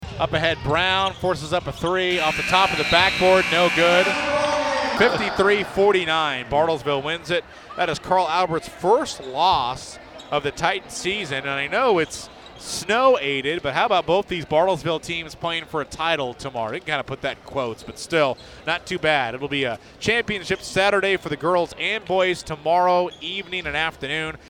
Here is how the final horn sounded on KWON.